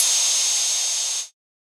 UHH_ElectroHatC_Hit-16.wav